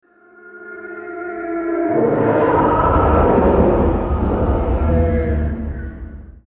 Deep Sea Monster Roar Sound Button - Free Download & Play